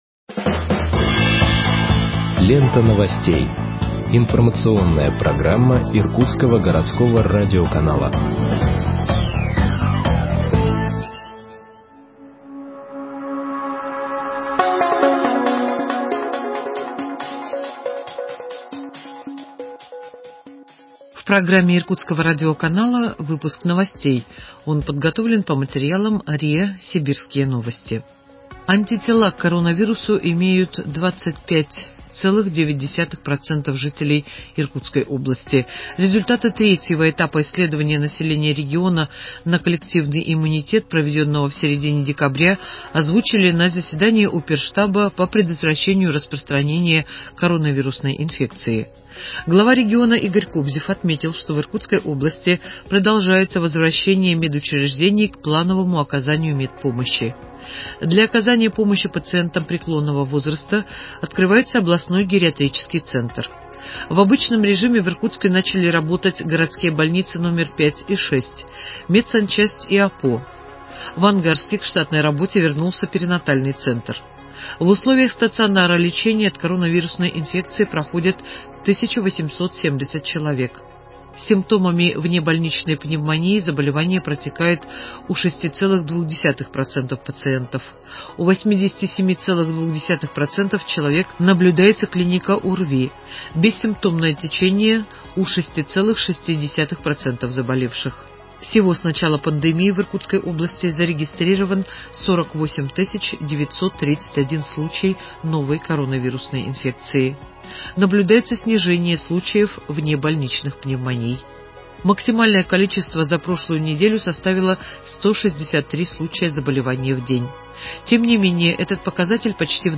Выпуск новостей в подкастах газеты Иркутск от 02.02.2021 № 2